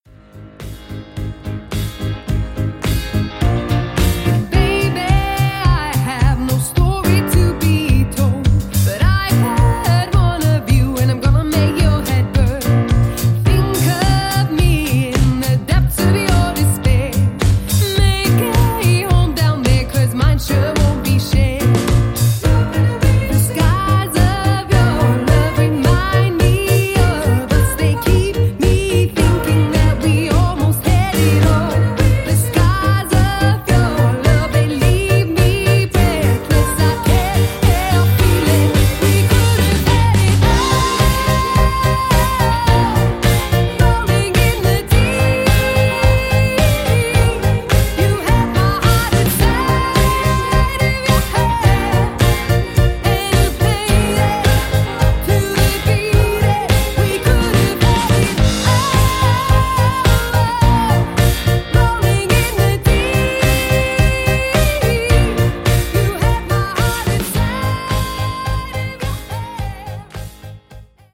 1. High-energy, sophisticated, four-piece party band